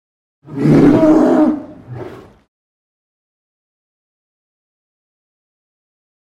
На этой странице собраны звуки полярного медведя – мощные рыки, ворчание и шаги по снегу.
Недовольный звук белого гиганта